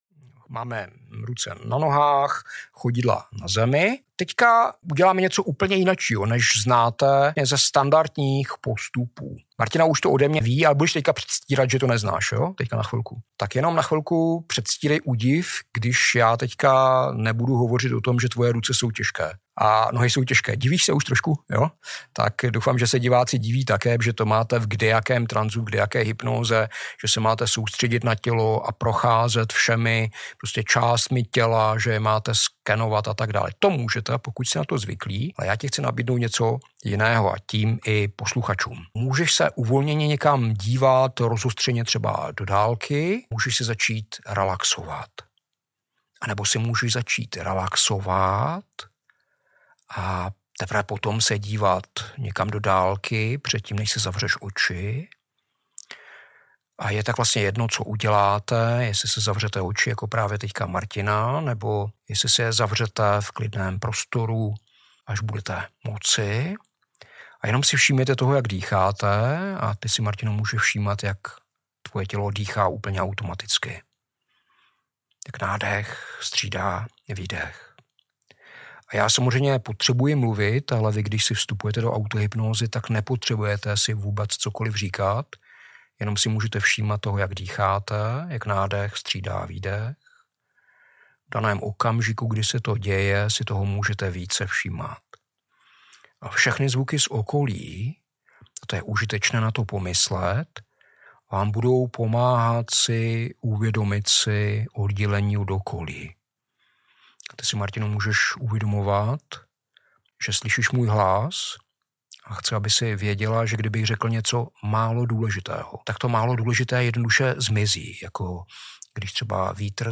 BONUS - Návod na autohypnózu - samostatně s hudbou
Nyní můžete trénovat autohypnózu každý den. Připravili jsme pro vás autohypnotické provázení s příjemnou hudbou, s kterým jste se seznámili v předchozím díle.